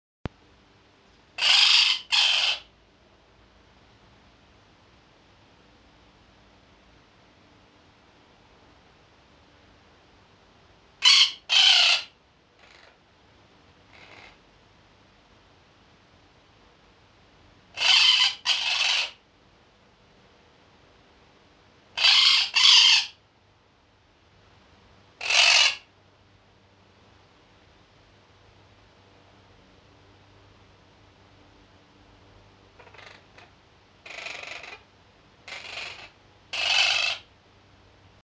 Download Quaker Parrot Screams sound effect for free.
Quaker Parrot Screams